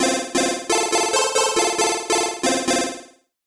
8-bit 8bit Game Jingle Nostalgic Old-School SFX Video-Game sound effect free sound royalty free Gaming